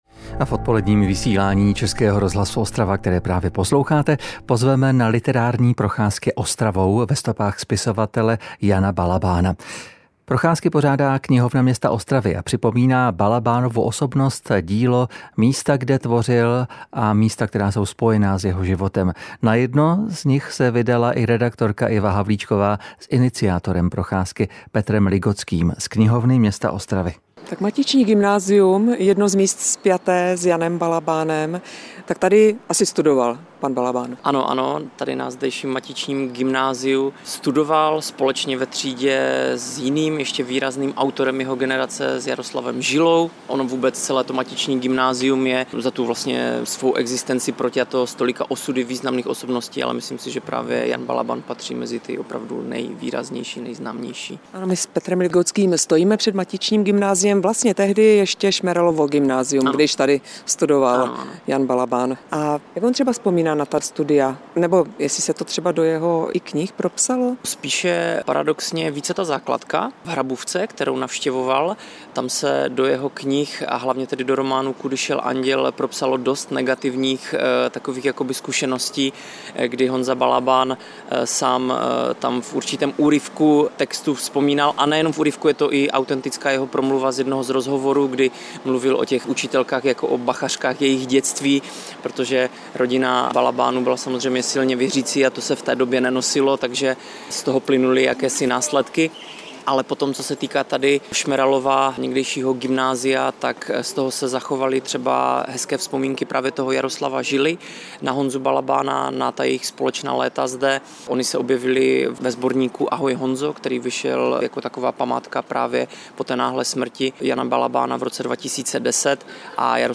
Odpolední interview: Patnáct let po smrti ostravského spisovatele Jana Balabána si ho lidé mohou opět připomenout - 19.06.2025